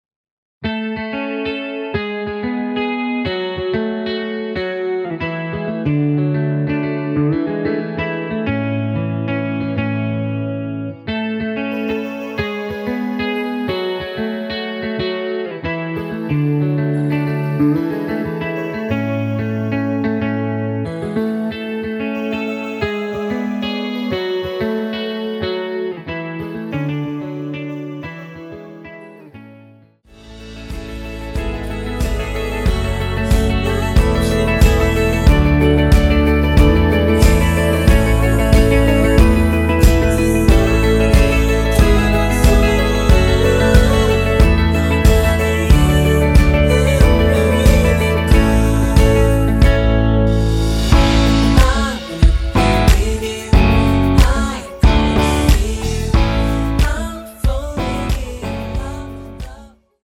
원키(2절 삭제)하고 진행 되는 멜로디와 코러스 포함된 MR입니다.(미리듣기 확인)
앞부분30초, 뒷부분30초씩 편집해서 올려 드리고 있습니다.